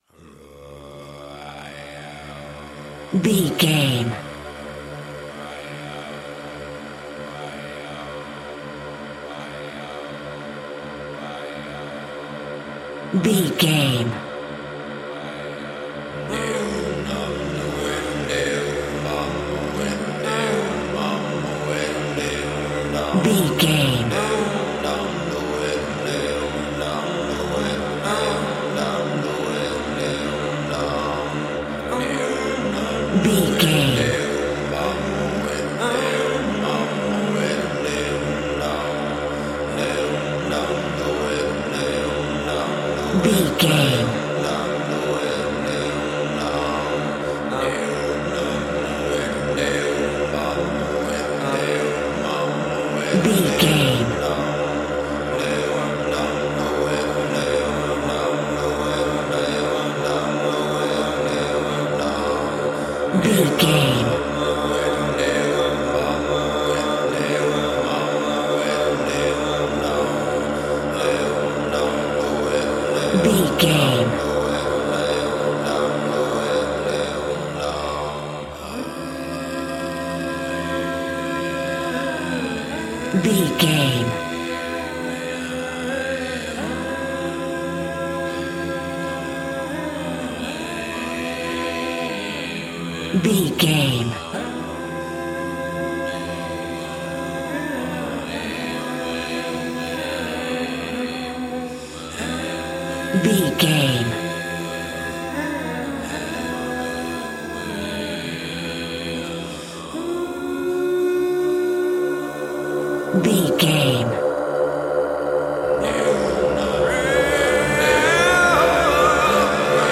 Ionian/Major
groovy
inspirational